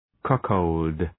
Shkrimi fonetik {‘kʌkəld}
cuckold.mp3